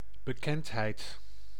Ääntäminen
IPA : /ˈfeɪm/